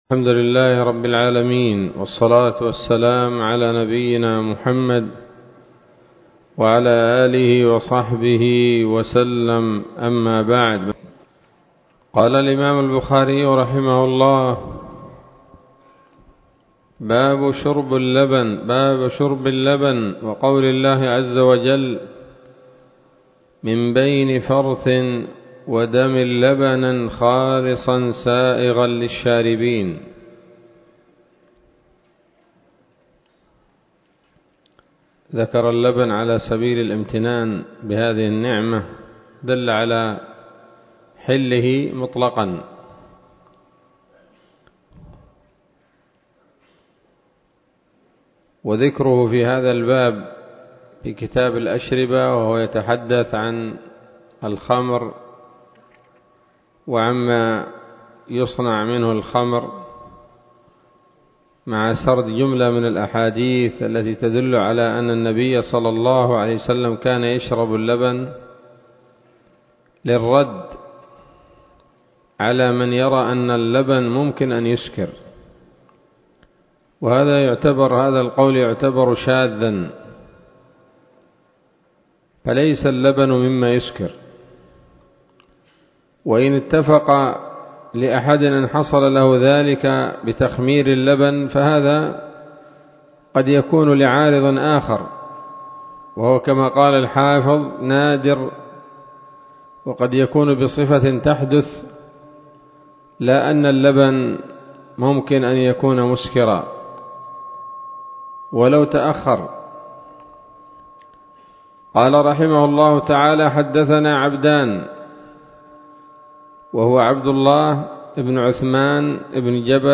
الدرس الحادي عشر من كتاب الأشربة من صحيح الإمام البخاري